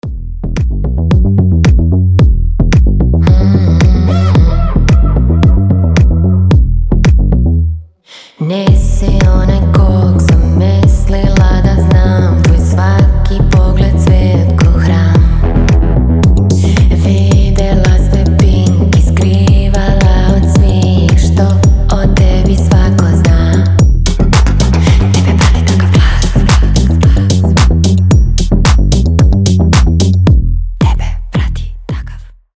• Качество: 320, Stereo
женский голос
чувственные
RnB
house